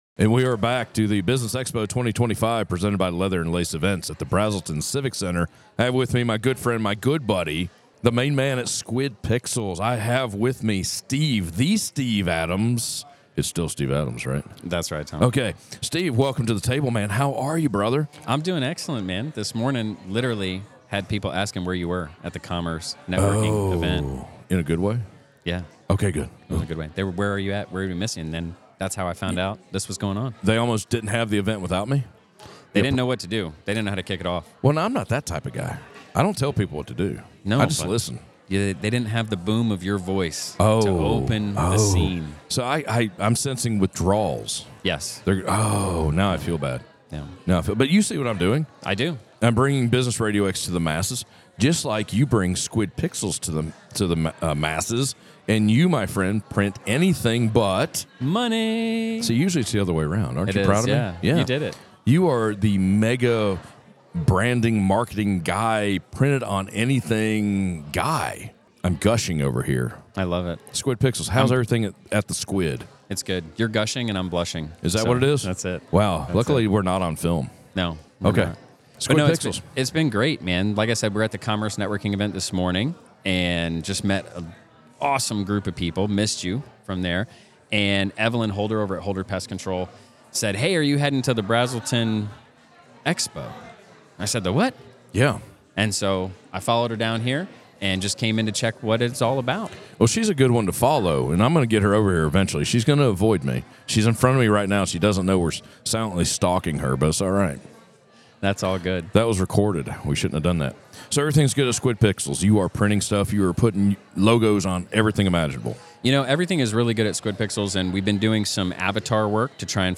Northeast Georgia Business RadioX – the official Podcast Studio of the Business Expo 2025